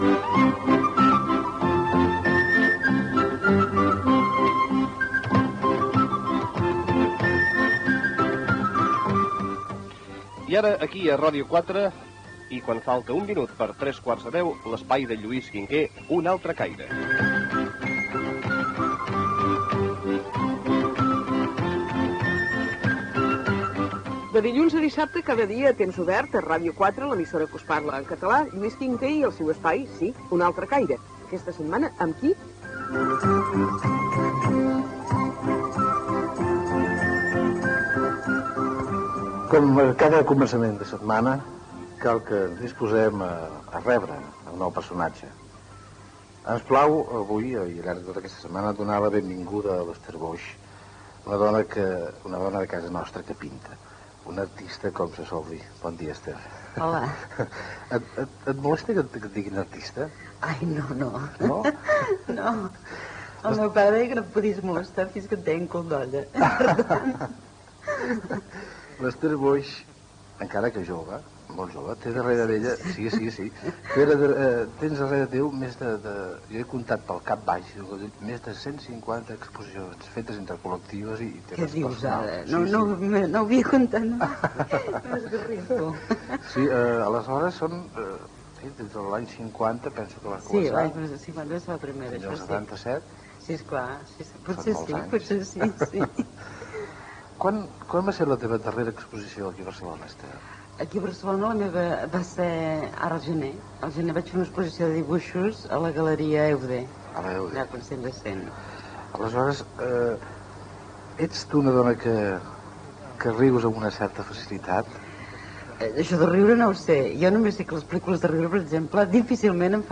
Espai "Un altre caire". Entrevista a la pintora Esther Boix
Info-entreteniment